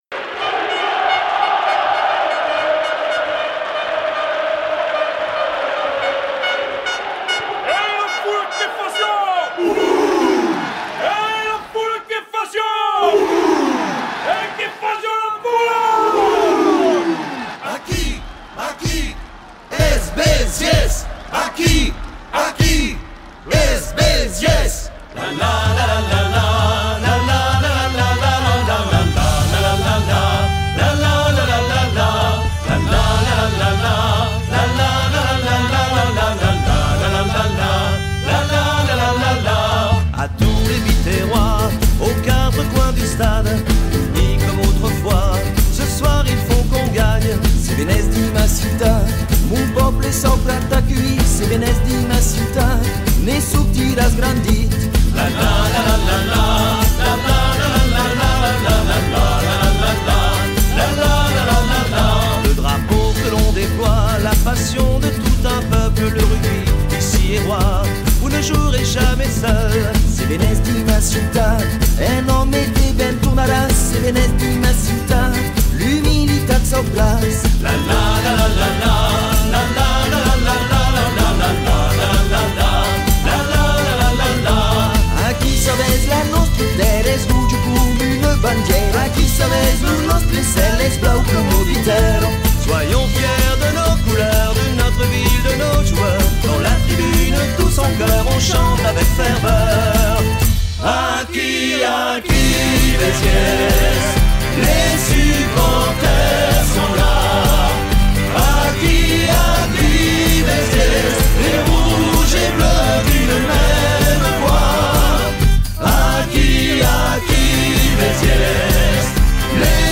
hymne